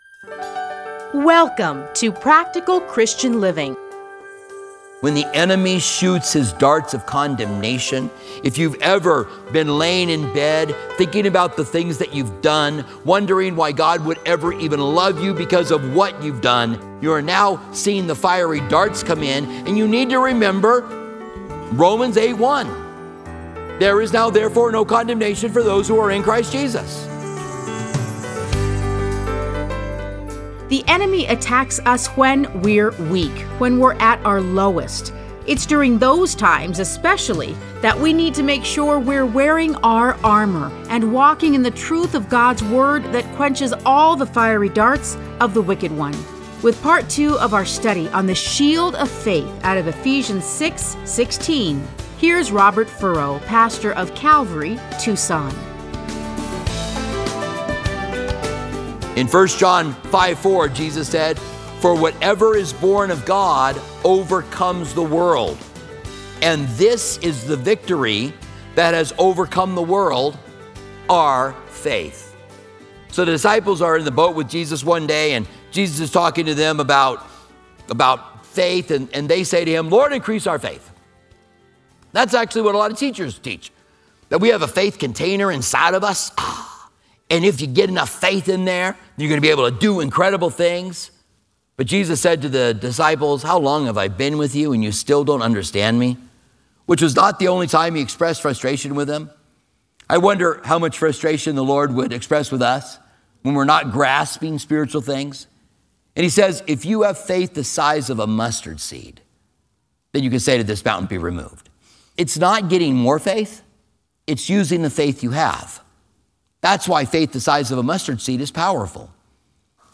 Listen to a teaching from Ephesians 6:16.